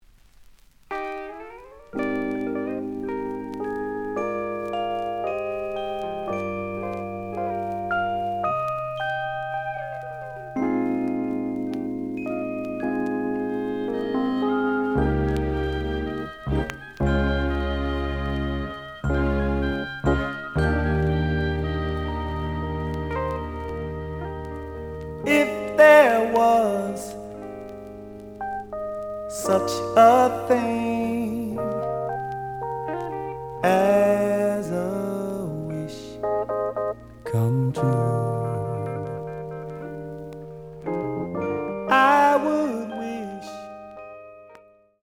The audio sample is recorded from the actual item.
●Genre: Funk, 70's Funk
Some noise on first half of B side, but almost good.)